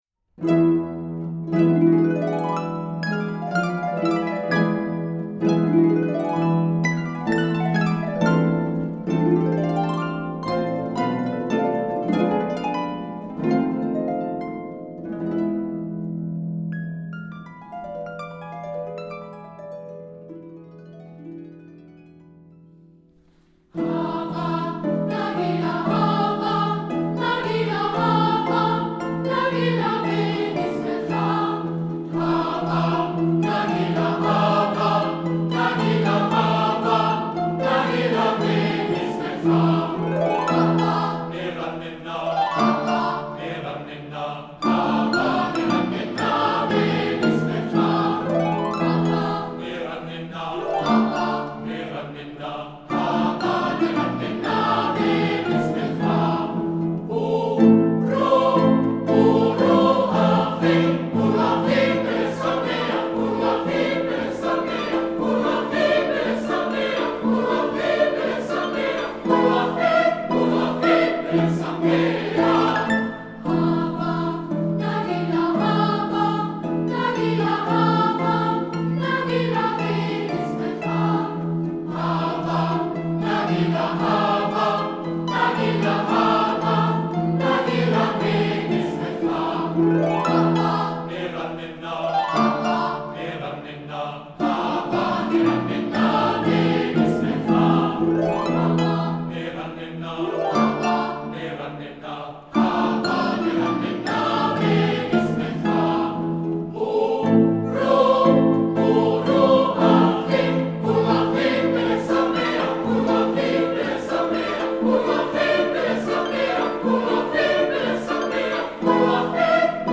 for four pedal harps and choir (SATB)